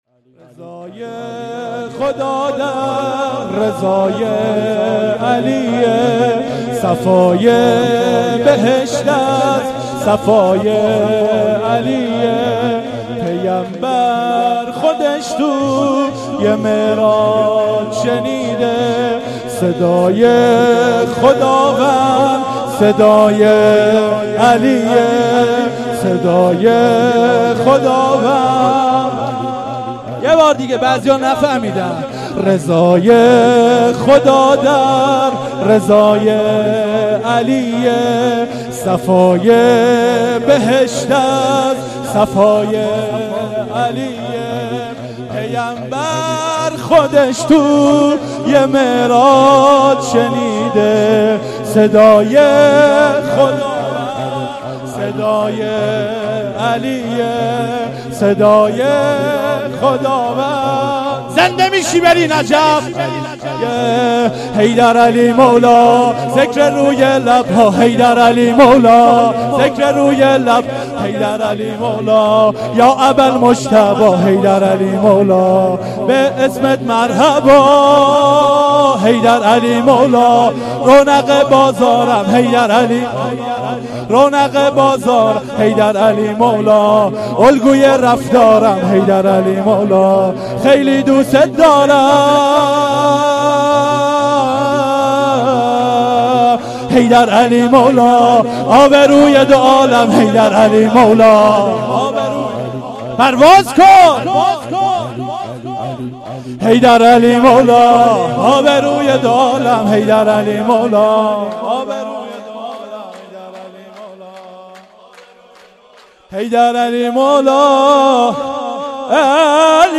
شور«4»
شور4.mp3